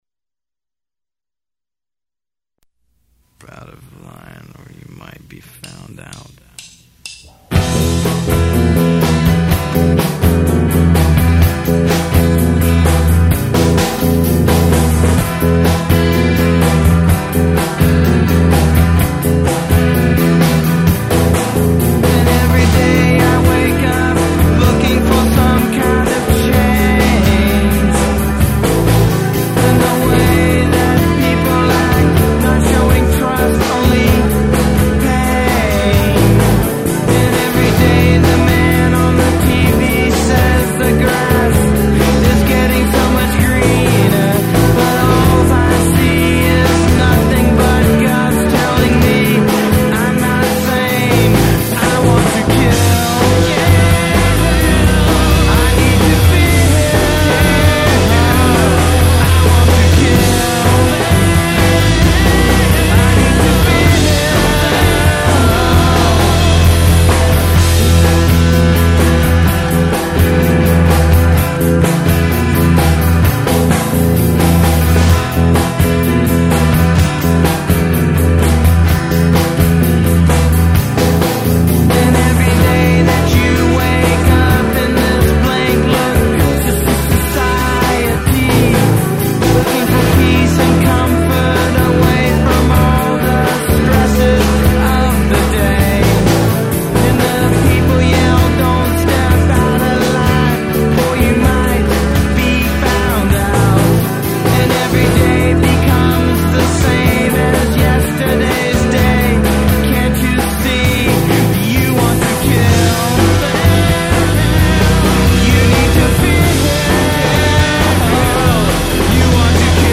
textures to bring the music all together.
at Cro-Magnon Studios.